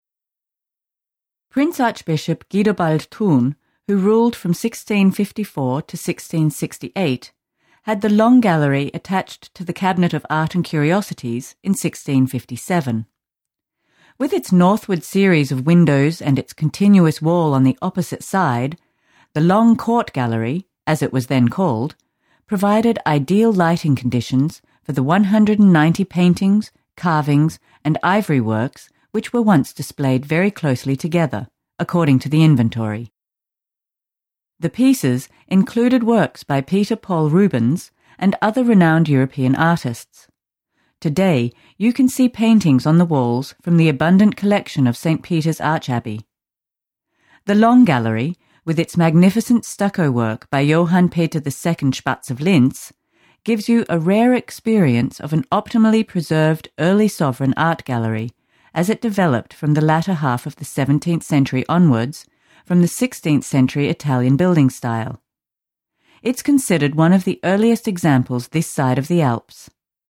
An audio guide is available to you during your visit to the DomQuartier.